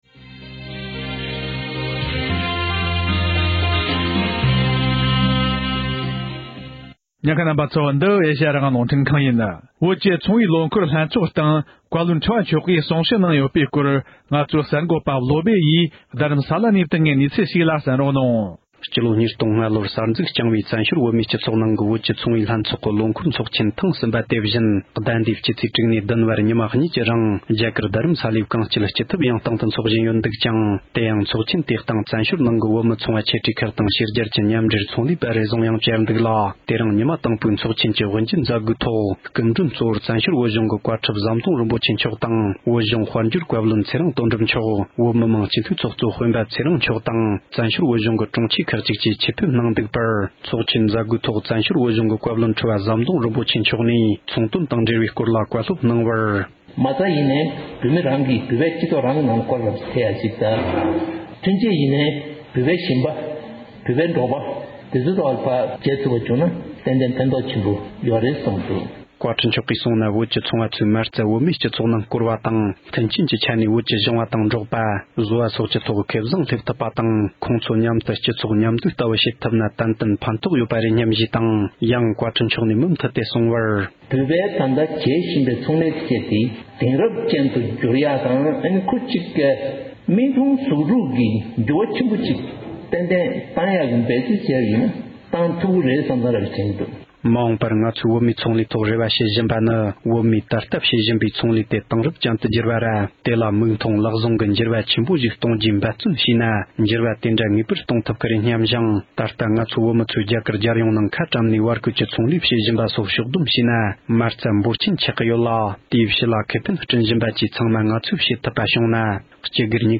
བཙན་བྱོལ་བོད་གཞུང་བཀའ་བློན་ཁྲི་པ་མཆོག་གིས་བོད་མིའི་ཚོང་ལས་བདེ་ཚོགས་ཀྱི་ཚོགས་ཆེན་ཐེངས་གསུམ་པའི་ཐོག་གསུང་བཤད་གནང་ཡོད་པ།
སྒྲ་ལྡན་གསར་འགྱུར། སྒྲ་ཕབ་ལེན།